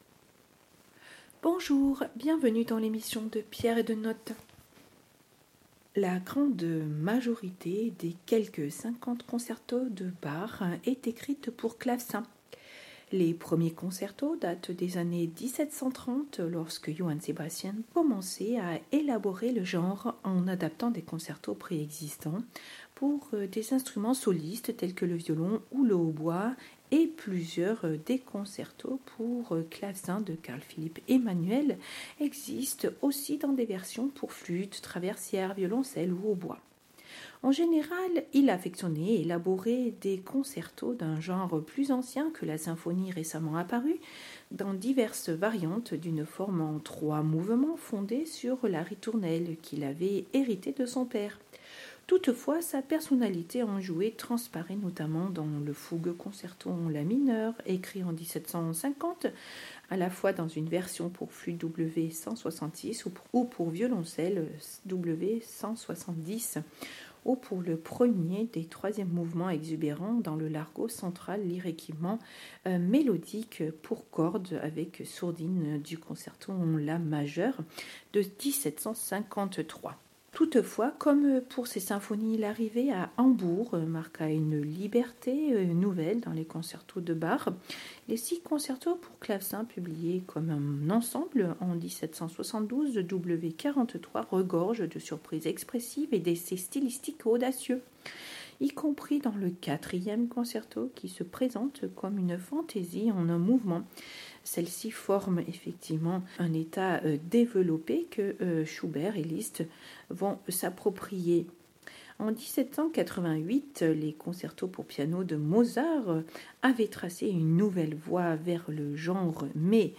dpdn-concerto_pour_clavecin_en_do_m_de_cpe_bach.mp3